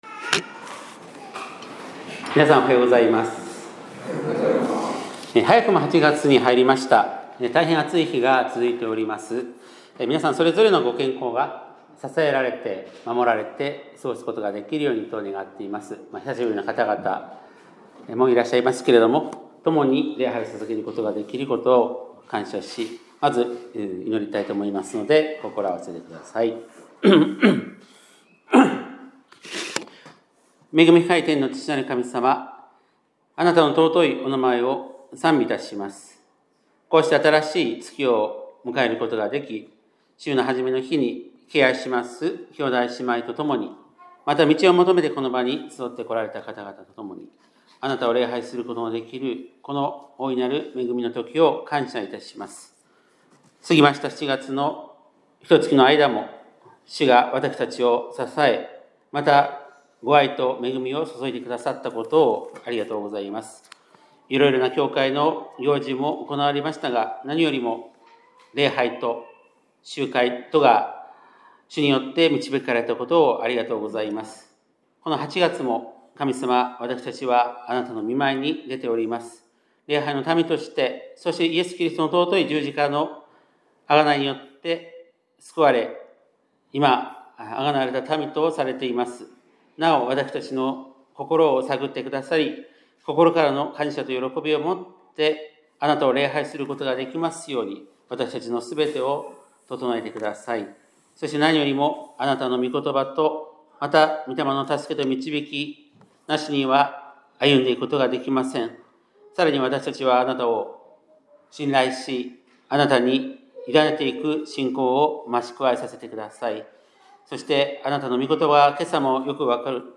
2025年８月3日（日）礼拝メッセージ